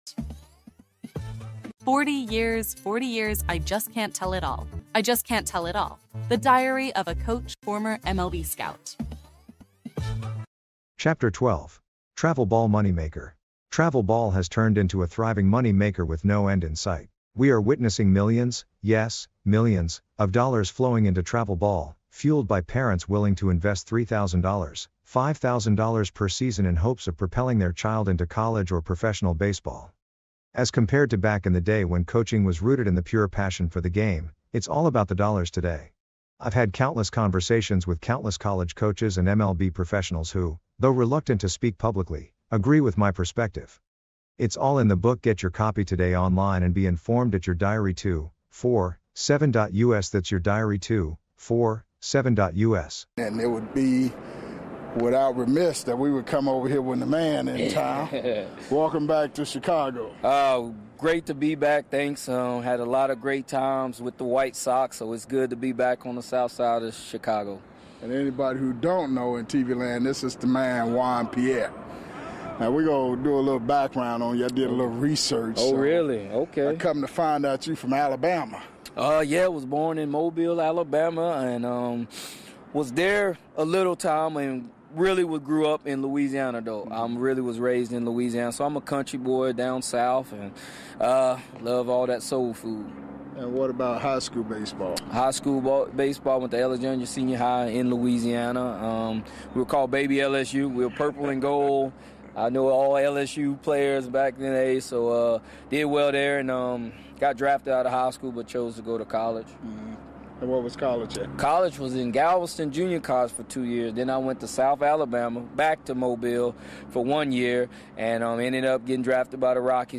MLB Classic Interviews – Coaches Corner